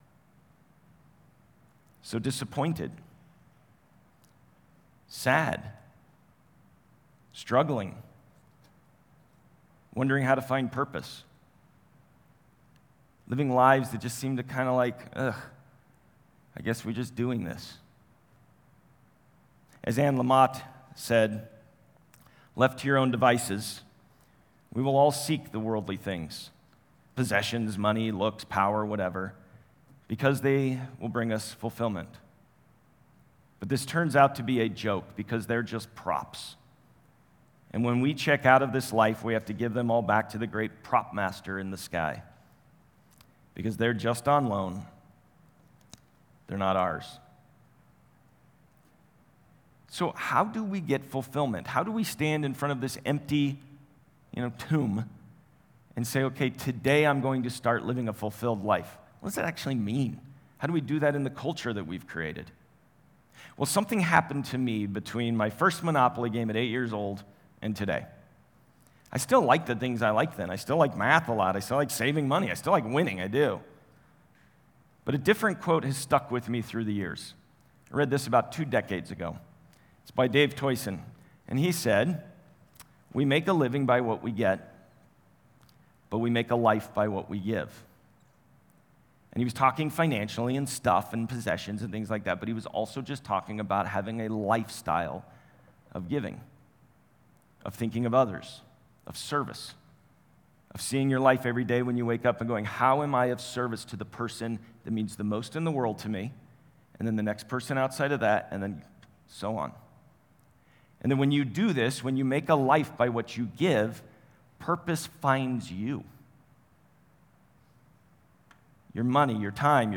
Watch or listen to recent Sunday messages and series from The Journey Church in Westminster, CO. New sermons posted weekly with video and notes.